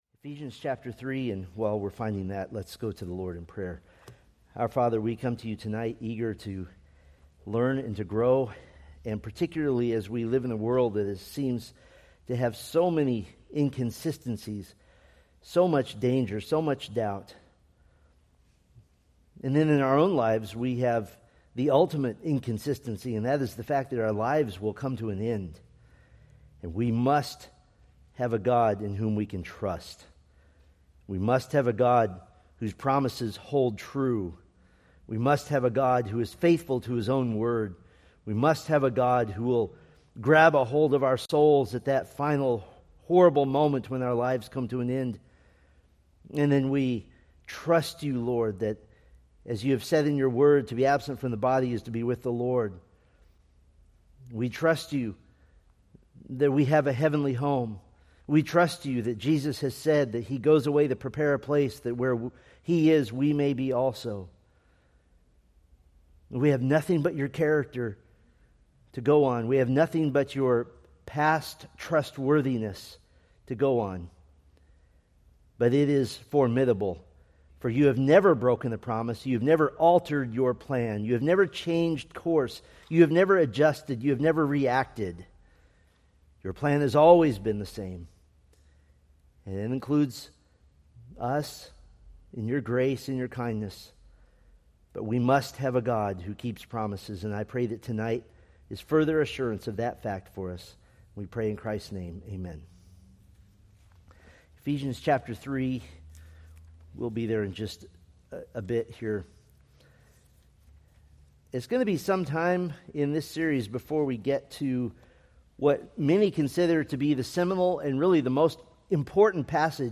Sermon audio from the preaching ministry of Grace Bible Church of Bakersfield, California.